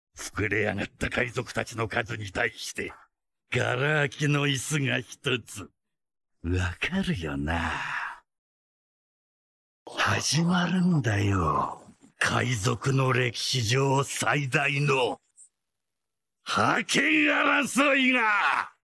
Download “doflamingo laugh 2” doflamingo-laugh-2.mp3 – Downloaded 6665 times – 301.26 KB